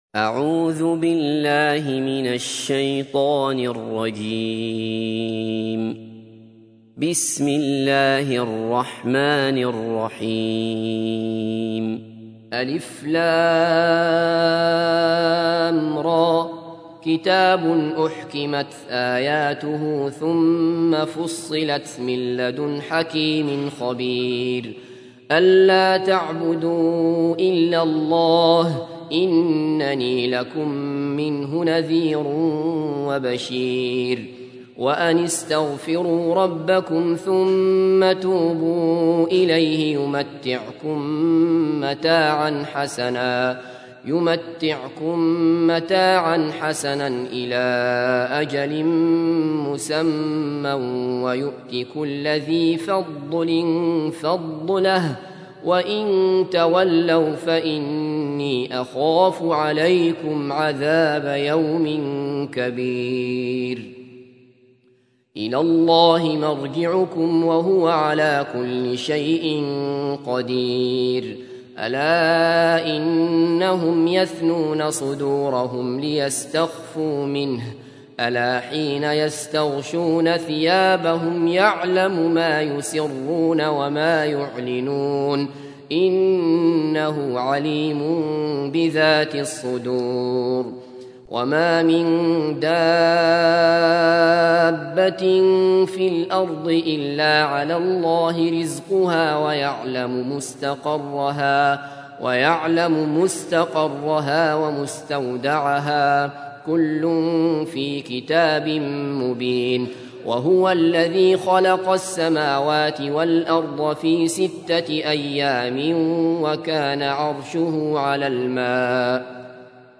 تحميل : 11. سورة هود / القارئ عبد الله بصفر / القرآن الكريم / موقع يا حسين